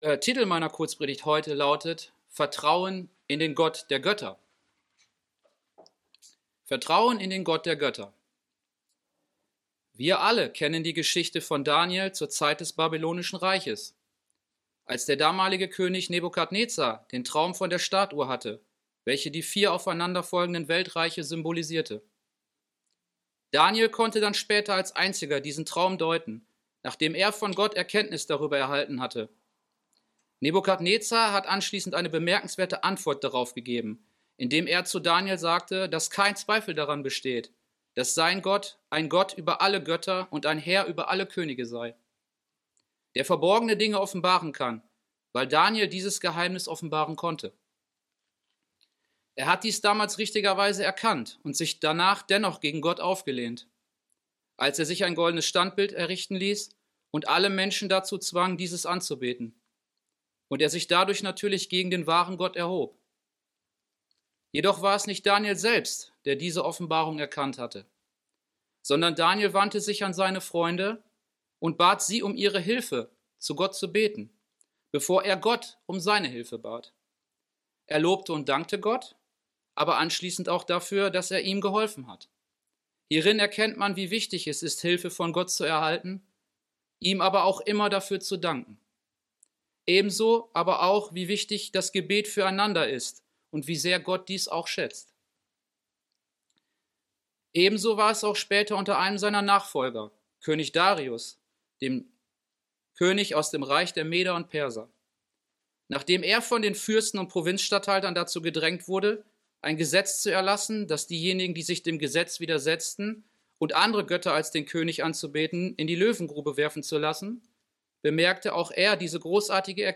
Diese Kurzpredigt will auch deutlich machen, dass man die Dinge ernst nehmen und sich JETZT darauf vorbereiten muss, bevor es zu spät ist!